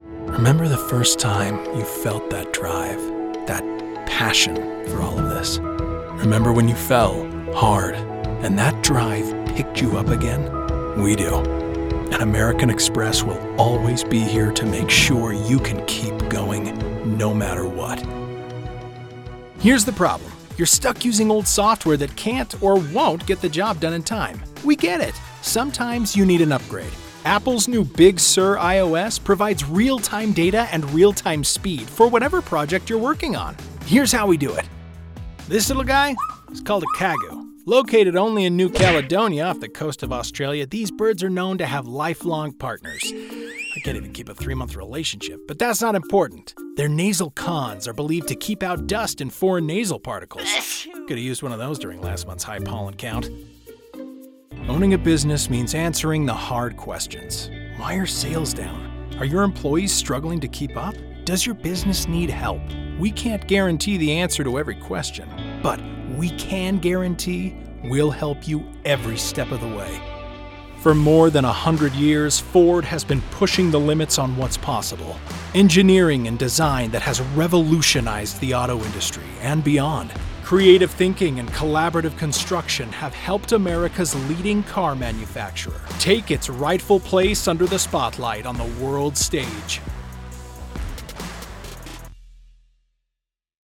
Narration↓ Download
Professionally-built, broadcast quality, double-walled LA Vocal Booth.